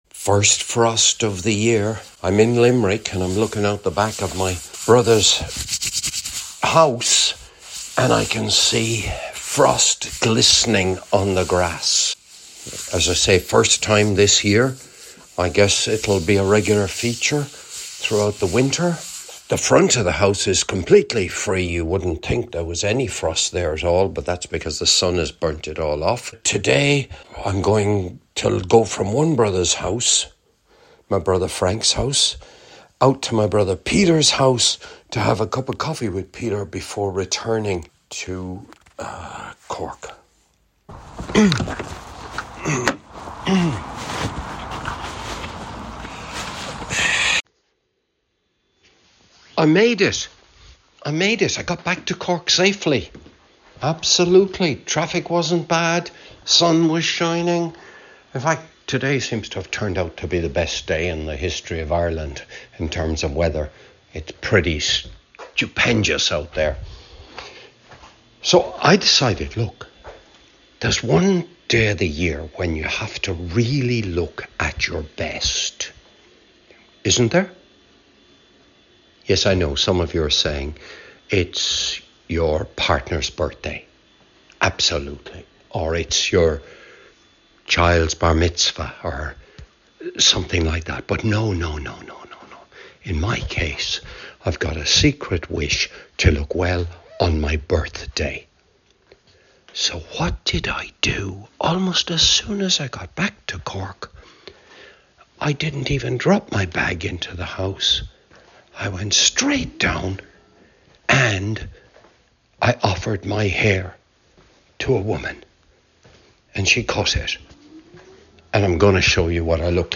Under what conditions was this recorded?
Recorded in Limerick & Cork on 11th October